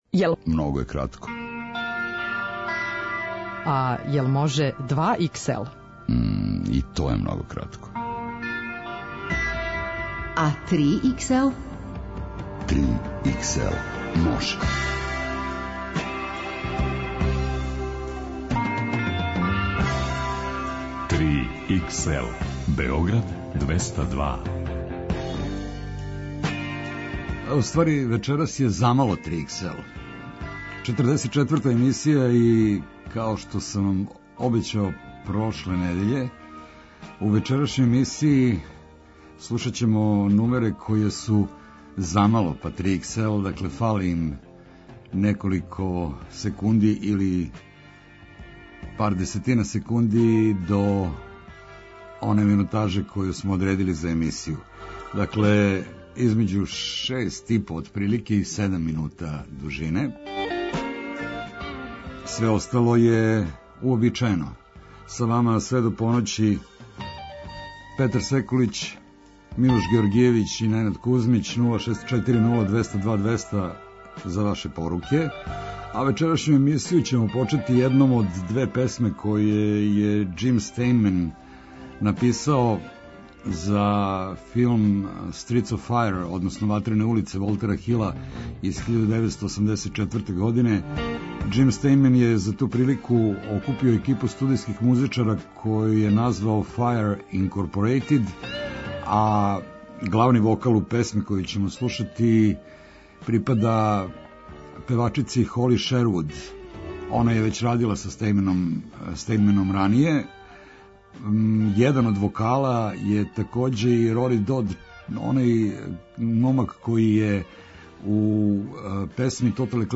Најдуже музичке нумере.